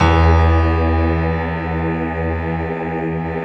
SI1 PIANO01R.wav